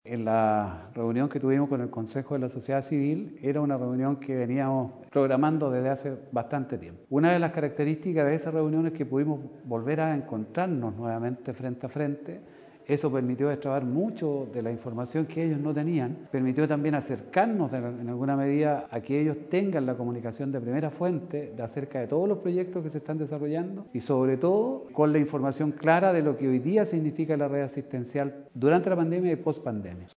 Director-SSV-Victor-Hugo-Jaramillo-reunion-con-COSOC.mp3